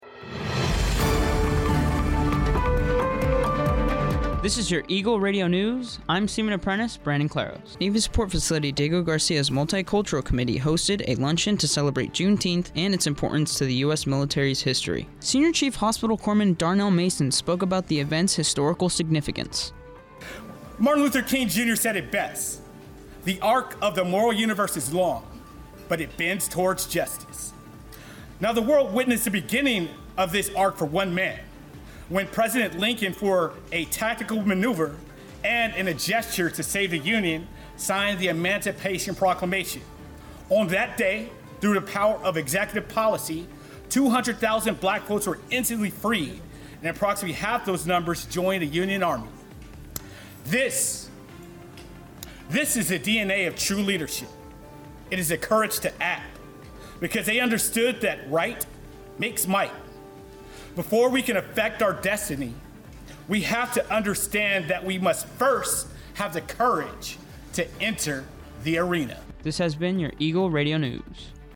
Eagle Radio News is the American Forces Network Diego Garcia’s official radio newscast. It is produced by U.S. Navy Sailors stationed onboard Navy Support Facility Diego Garcia.